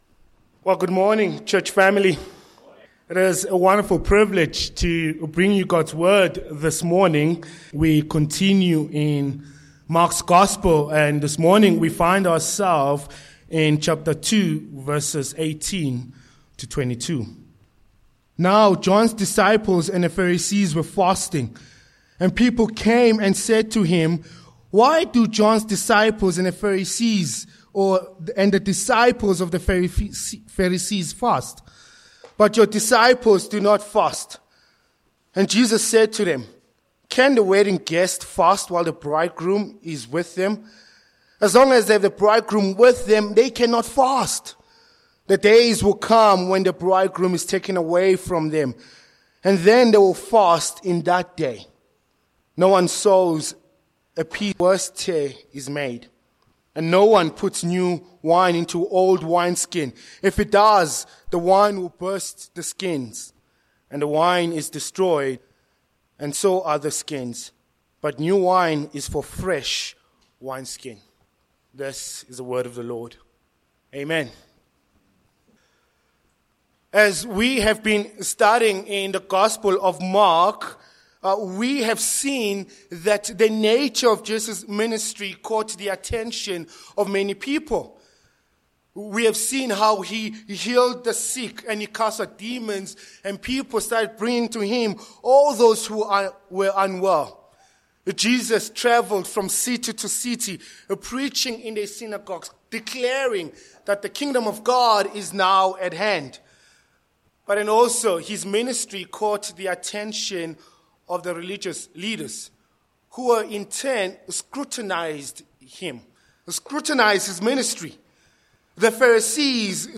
Mark 2:18-22 Service Type: Morning Passage